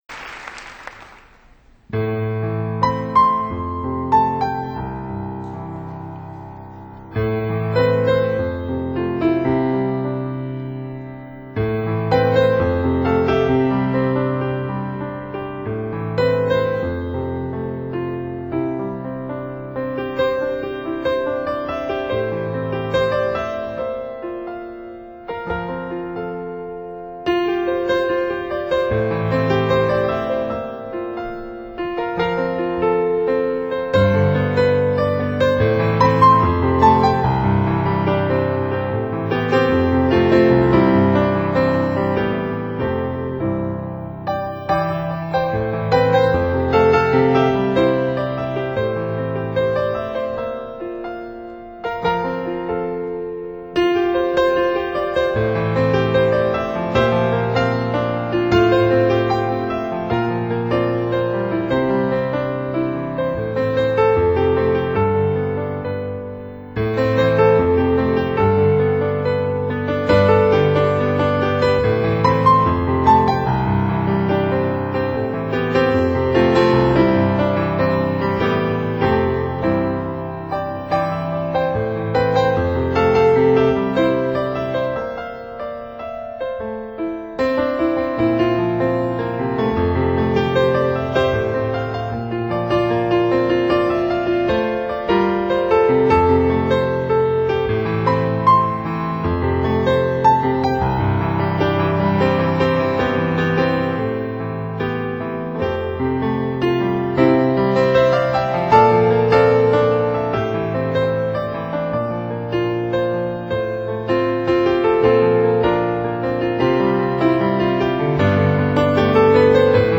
recorded live in Salt Lake City in March, 2000.